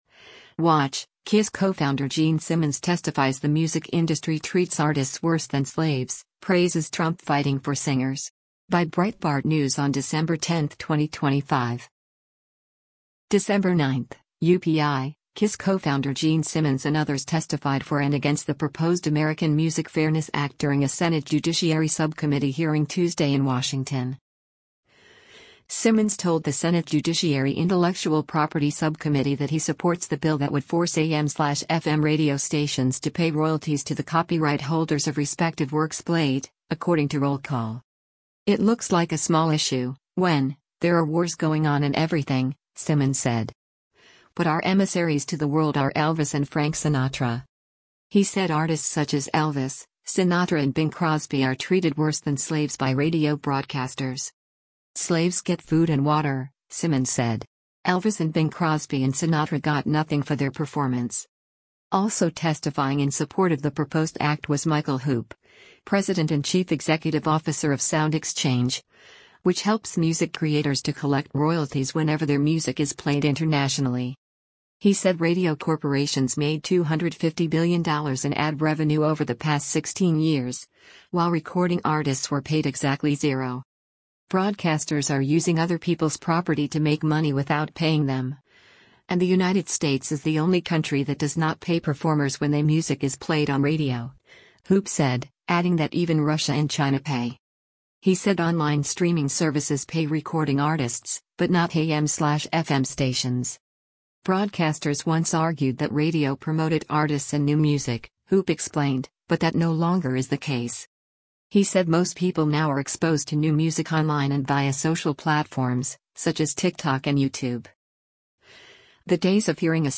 Watch: KISS Co-Founder Gene Simmons Testifies the Music Industry Treats Artists 'Worse Than Slaves,' Praises Trump Fighting for Singers
Dec. 9 (UPI) — KISS co-founder Gene Simmons and others testified for and against the proposed American Music Fairness Act during a Senate Judiciary subcommittee hearing Tuesday in Washington.